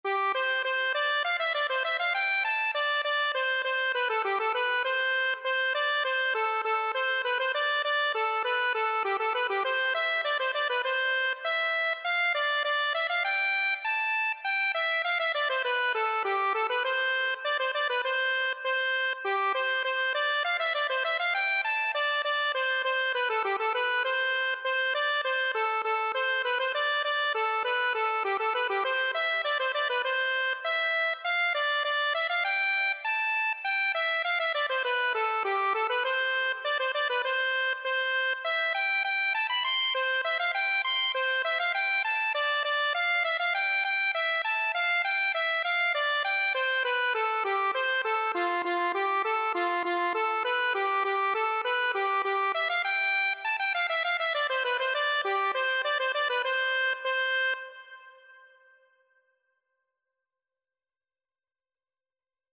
Traditional Turlough O Carolan Morgan Magan Accordion version
2/4 (View more 2/4 Music)
C major (Sounding Pitch) (View more C major Music for Accordion )
Accordion  (View more Easy Accordion Music)
Traditional (View more Traditional Accordion Music)